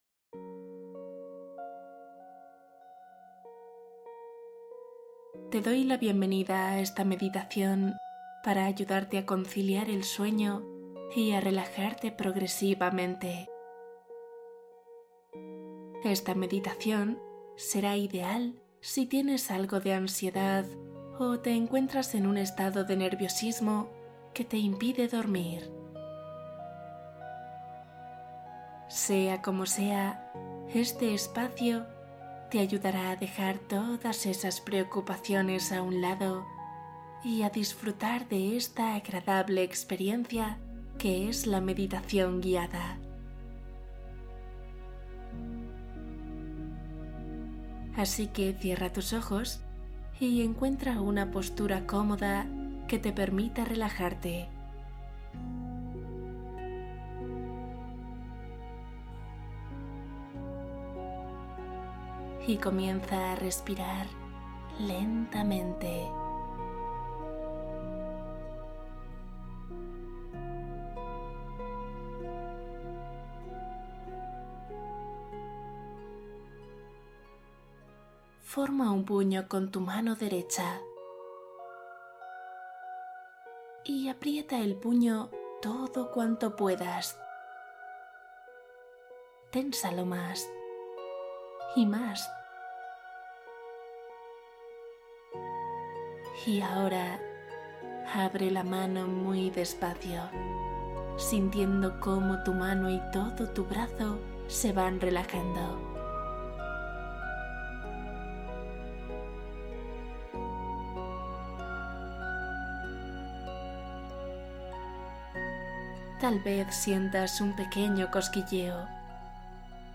Sueño profundo y manifestación: meditación poderosa para dormir